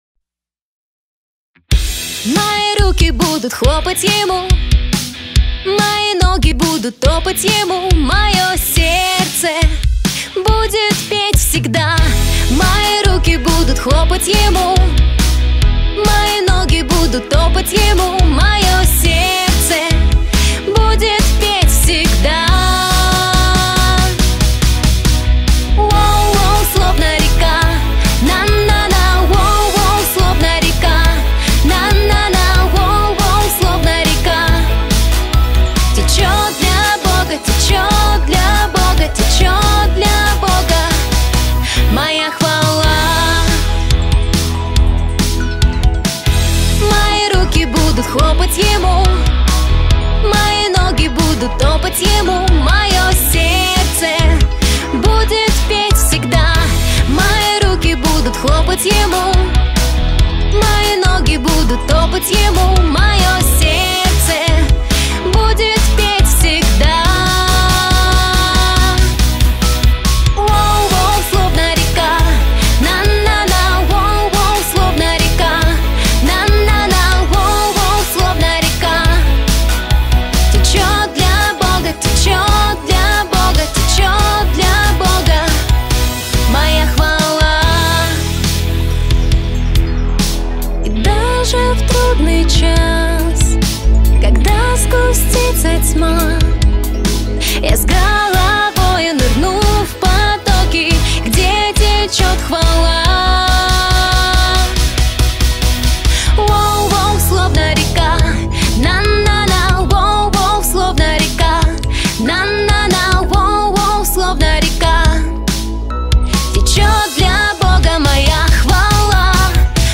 131 просмотр 158 прослушиваний 15 скачиваний BPM: 140